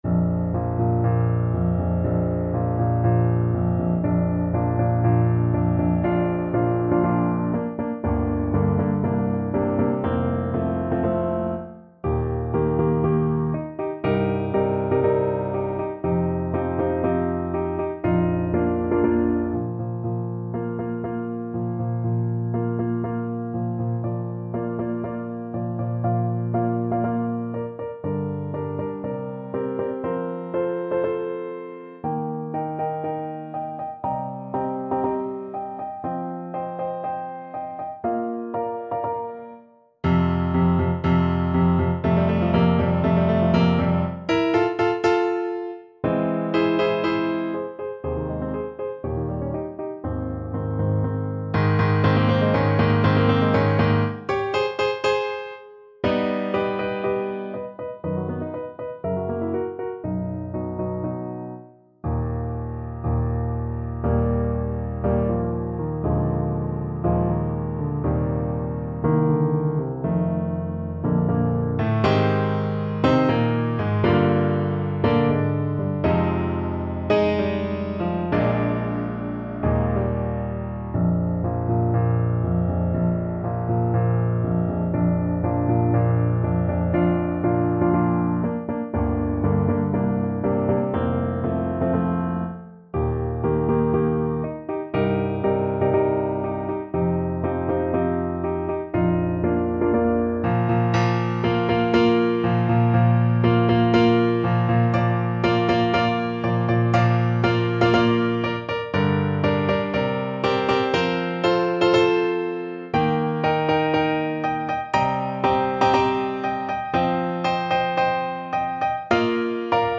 Play (or use space bar on your keyboard) Pause Music Playalong - Piano Accompaniment Playalong Band Accompaniment not yet available reset tempo print settings full screen
~ = 120 Tempo di Marcia un poco vivace
Bb major (Sounding Pitch) (View more Bb major Music for Trombone )